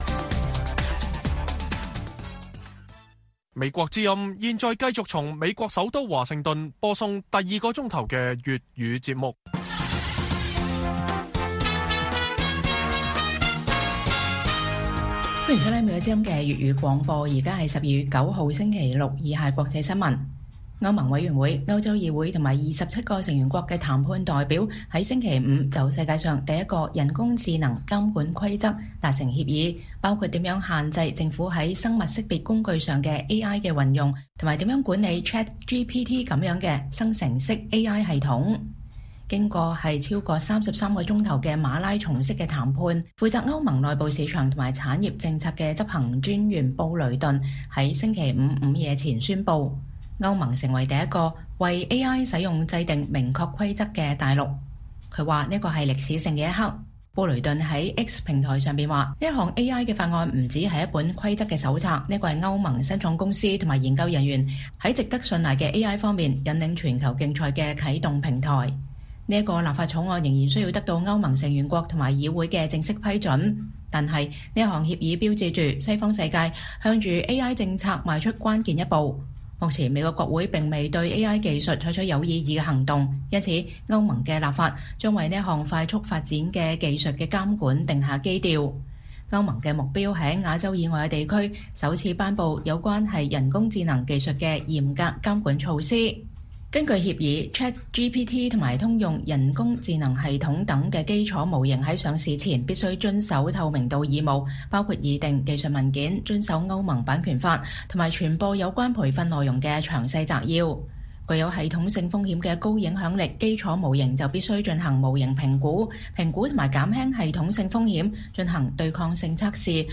粵語新聞 晚上10-11點: 歐盟敲定人工智慧監管協議 最快明年生效